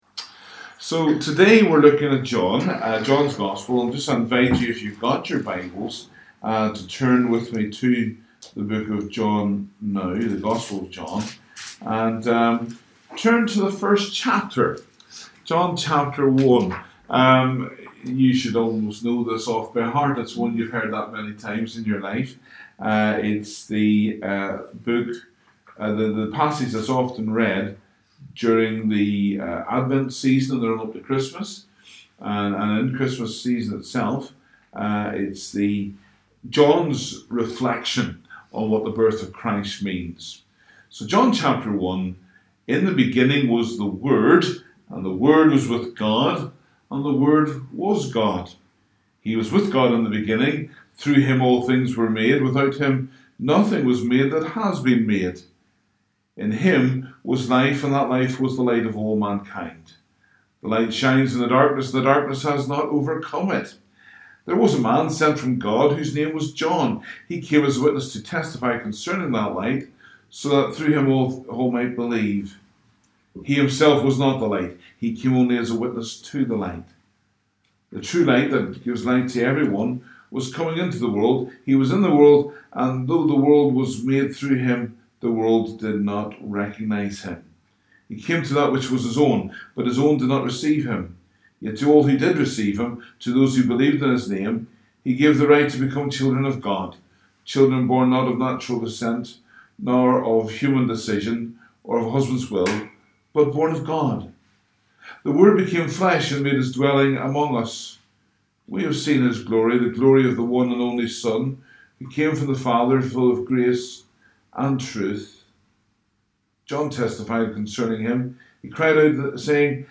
Download the live Session as an MP3 audio file (33.1 MB )